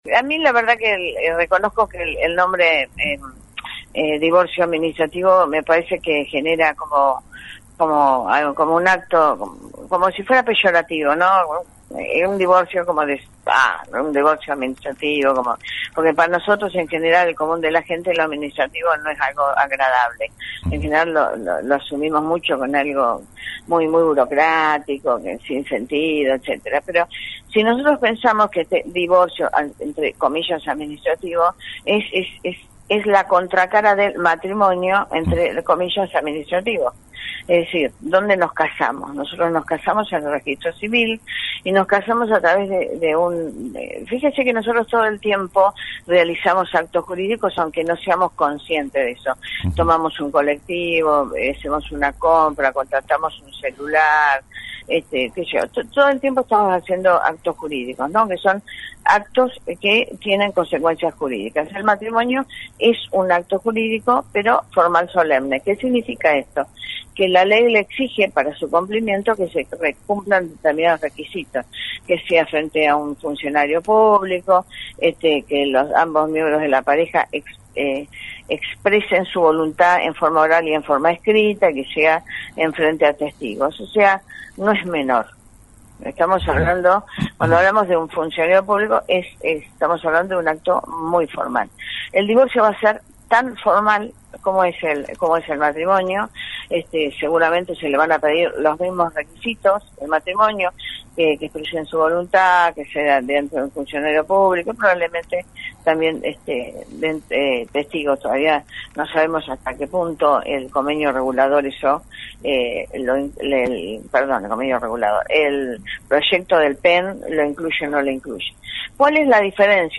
habló con la Jueza Silvia Monserrat Pta. Del Foro de Justicia de la Familia.